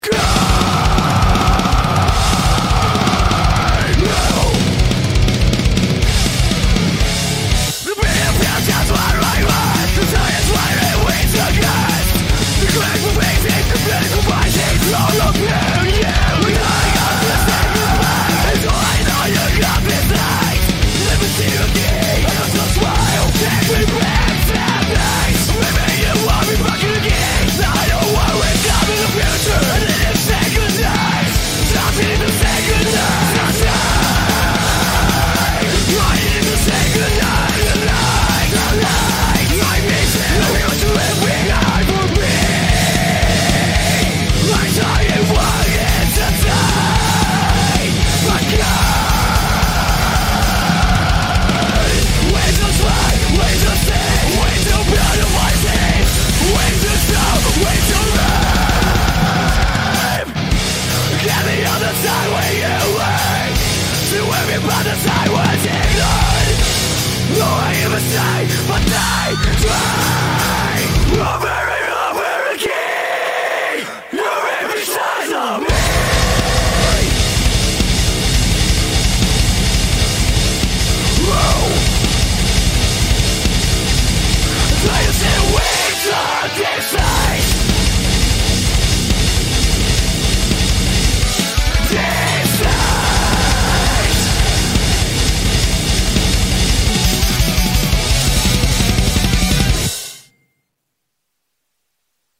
BPM158-244
Audio QualityPerfect (Low Quality)